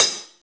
paiste hi hat4 close.wav